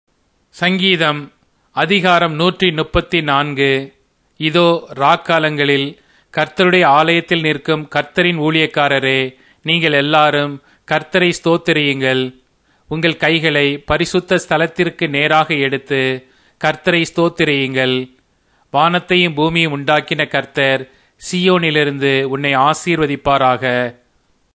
Tamil Audio Bible - Psalms 14 in Irvor bible version